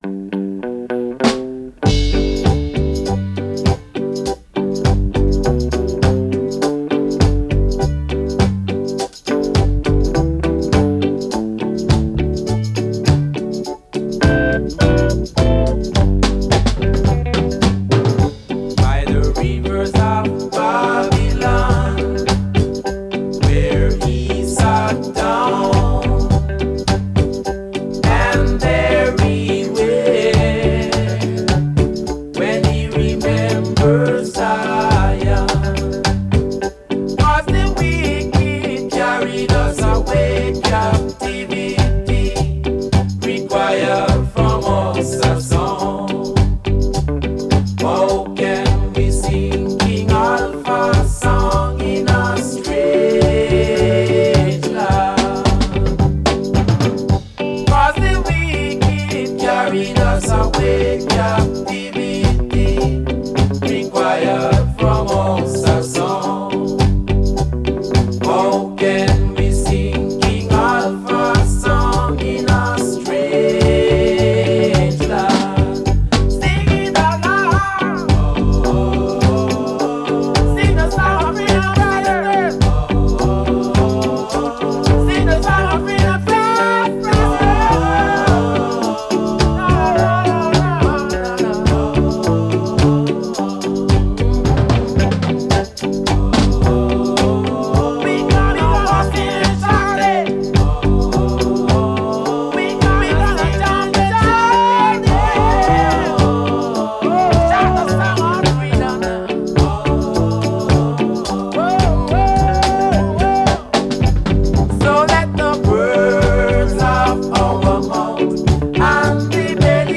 reggae style (“rock steady”)  Jamaican group